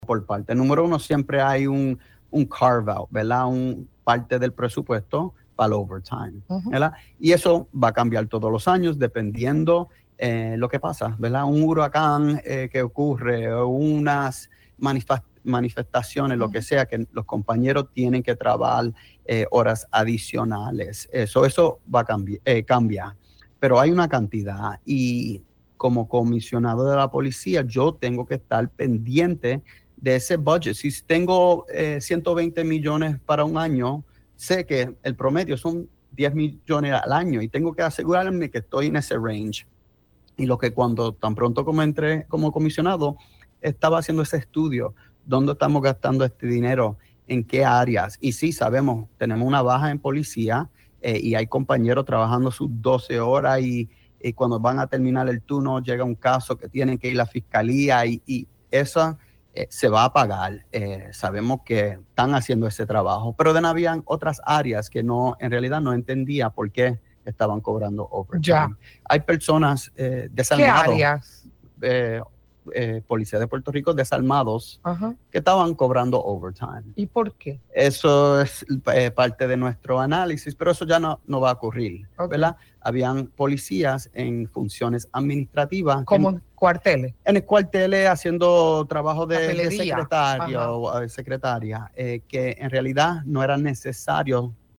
Eso es parte de nuestro análisis, pero eso ya no va a ocurrir. Había policías en funciones administrativas […] en el cuartel haciendo trabajo de secretaría que, en realidad, no era necesario hacer ese over time“, afirmó el comisionado en entrevista para El Calentón.
327-JOSEPH-GONZALEZ-COMISIONADO-POLICIA-AGENTES-DESARMADOS-Y-EMPLEADOS-ADMINISTRATIVOS-HACIENDO-HORAS-EXTRAS.mp3